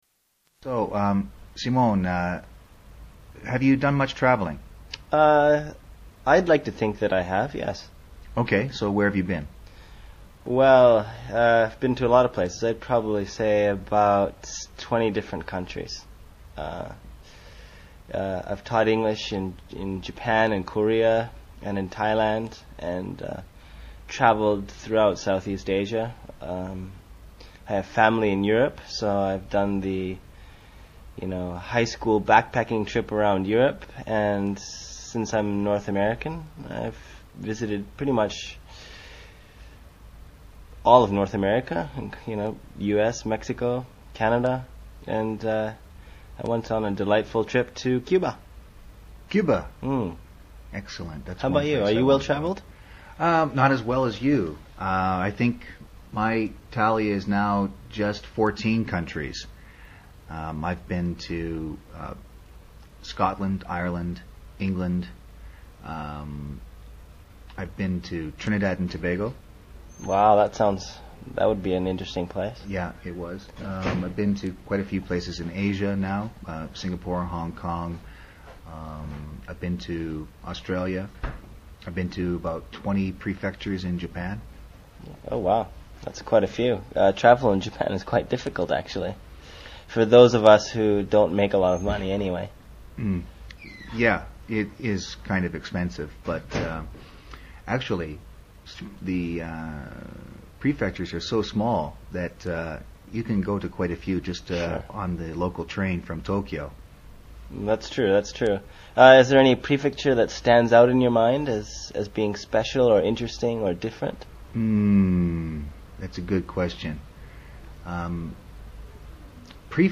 英语访谈对话 304 Travel 听力文件下载—在线英语听力室